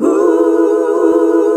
HUH SET E.wav